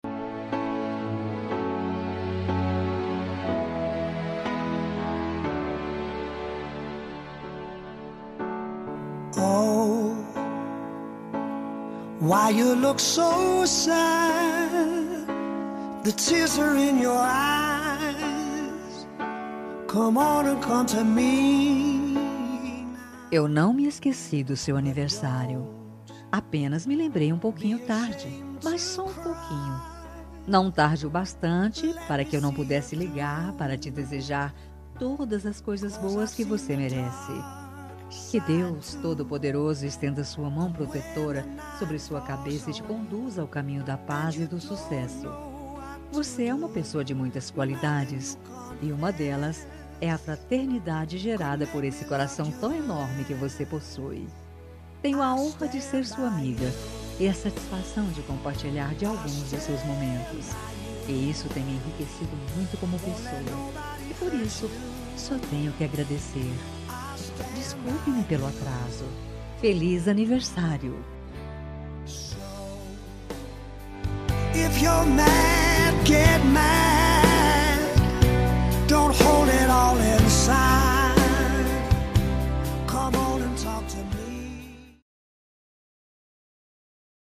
Aniversário Atrasado – Voz Feminina – Cód: 2496